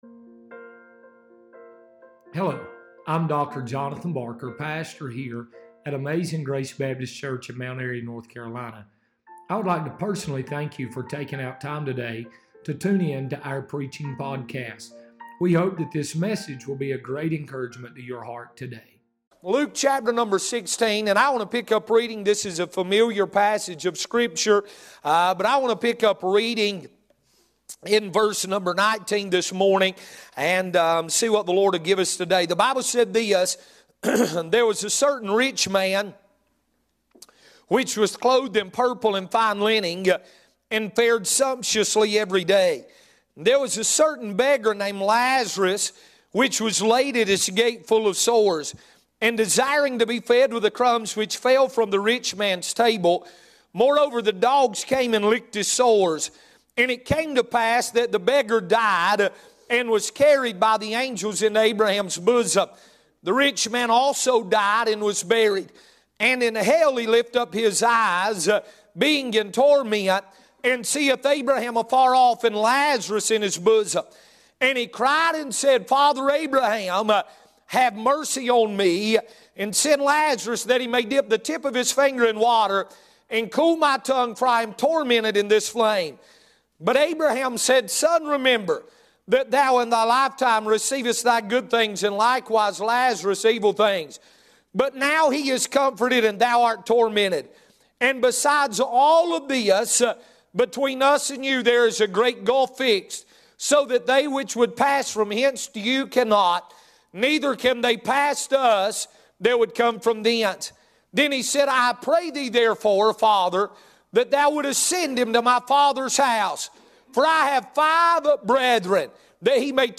Sermons | Amazing Grace Baptist Church